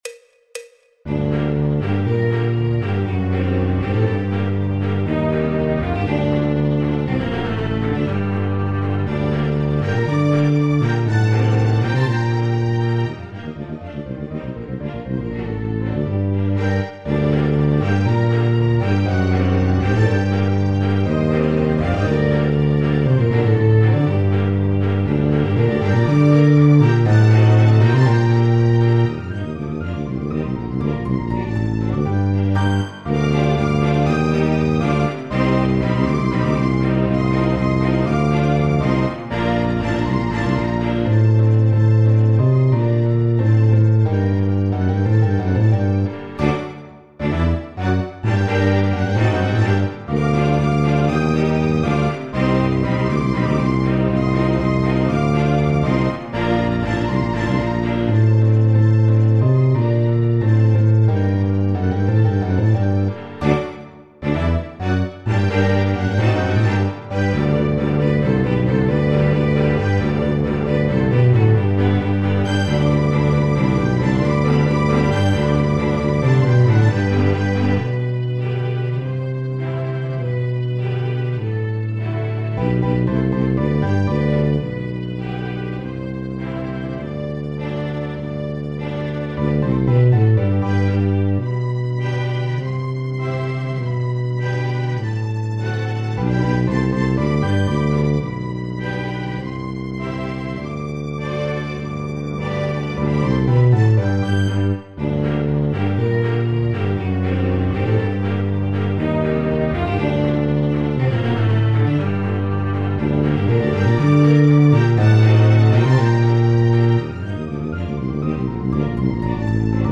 El MIDI tiene la base instrumental de acompañamiento.
Popular/Tradicional